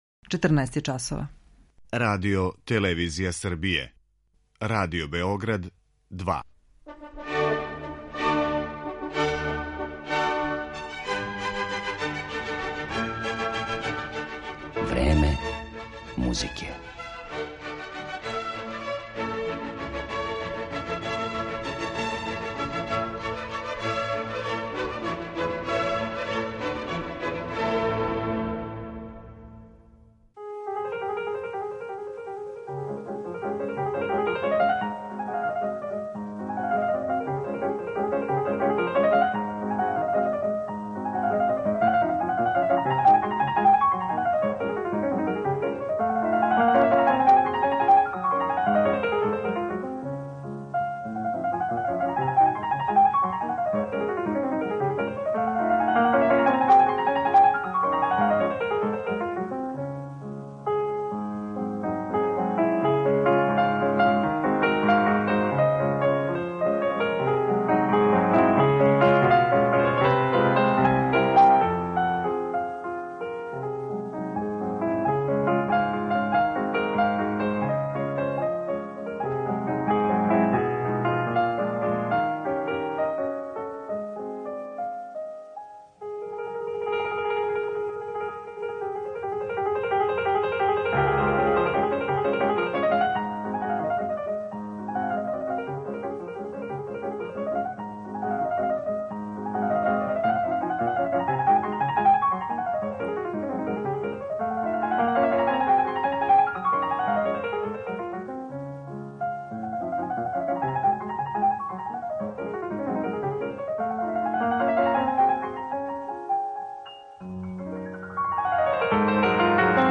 Ovaj slavni rumunski pijanista umro je sa samo 33 godine pre više od sedam decenija, decembra 1950, ali njegov uticaj i harizma i danas dopiru do novih generacija izvođača i slušalaca. Slušaćete ga kako izvodi dela Frederika Šopena, Franca Šuberta, Johana Sebastijana Baha, Volfganga Amadeusa Mocarta i Domenika Skarlatija.